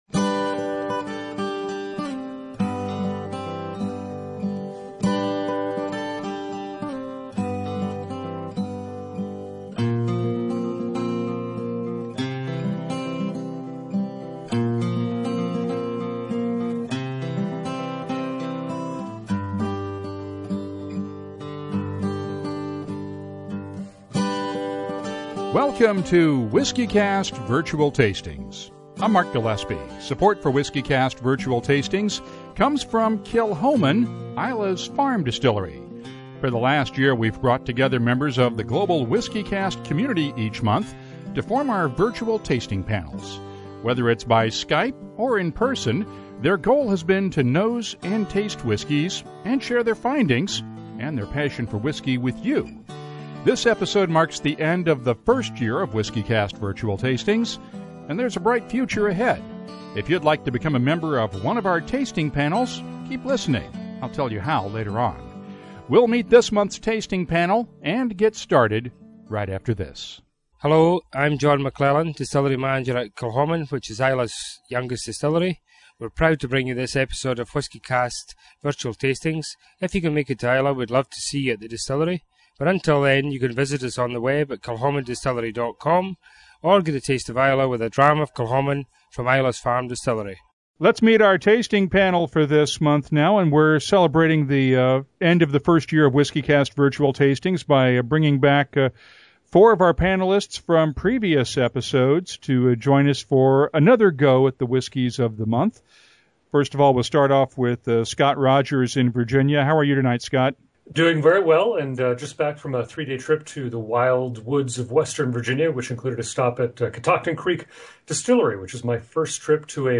I’ll leave it to you to listen to the show, but I will say that the panel was full of Texans, and at least one whisky we tried is a lone star product.